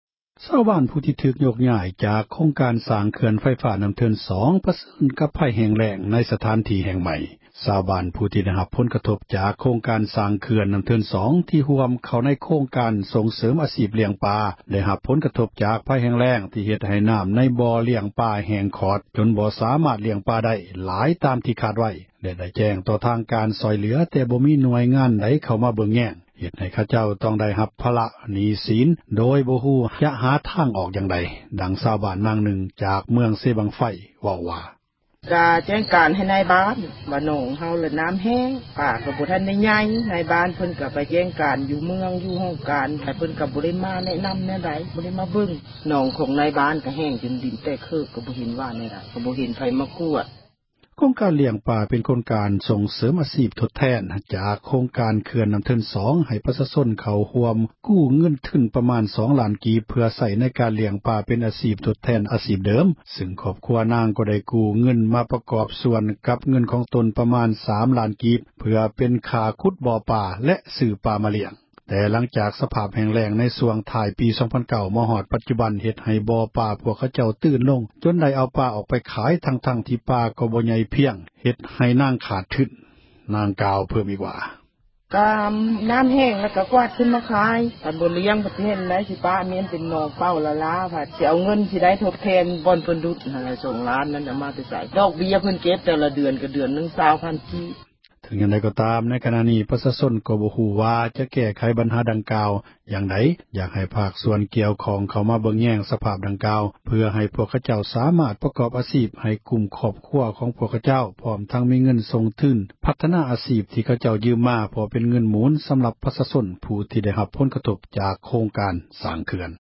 ຊາວບ້ານ ທີ່ໄດ້ຮັບ ຜົລກະທົບ ຈາກໂຄງການ ເຂື່ອນນ້ຳເທີນສອງ ທີ່ຮ່ວມເຂົ້າ ໃນໂຄງການ ສົ່ງເສີມ ອາຊີພລ້ຽງປາ ໄດ້ຮັບຄວາມ ເສັຽຫາຍ ຈາກພັຍ ແຫ້ງແລ້ງ ທີ່ເຮັດໃຫ້ ນ້ຳໃນບໍ່ ລ້ຽງປາ ຂອດແຫ້ງ ຈົນບໍ່ສາມາດ ລ້ຽງປາ ໄດ້ຫລາຍ ຕາມຄາດຫມາຍ ແລະໄດ້ແຈ້ງ ຕໍ່ທາງການ ໃຫ້ຊ່ວຍເຫລືອ ແຕ່ບໍ່ມີ ຫນ່ວຍງານໃດ ເຂົ້າມາ ເບີ່ງແຍງ ເຮັດໃຫ້ຂະເຈົ້າ ຕ້ອງໄດ້ຮັບ ພາຣະ ບັນຫາຫນີ້ສີນ ໂດຍບໍ່ຮູ້ ຈະຫາທາງ ອອກຢ່າງໃດ ດັ່ງຊາວບ້ານ ນາງນຶ່ງ ຈາກເມືອງ ເຊບັງໄຟ ເວົ້າວ່າ: